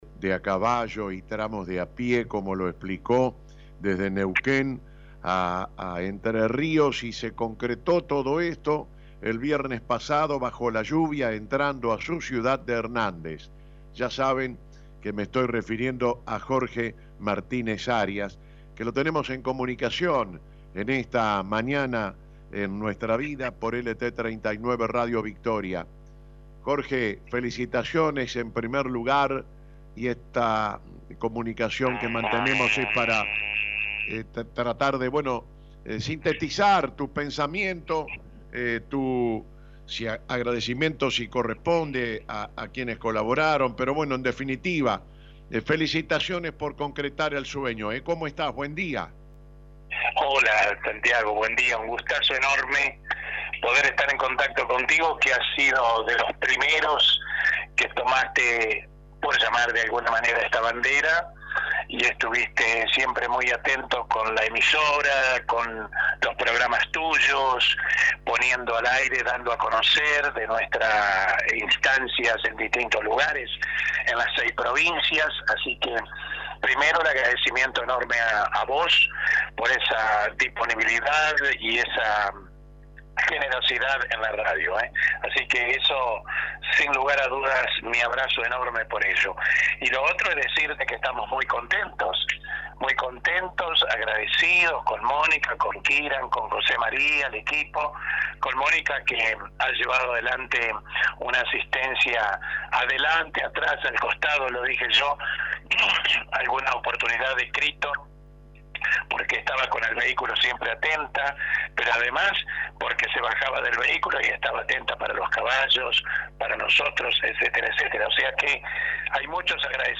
En una entrevista exclusiva en LT39 Radio Victoria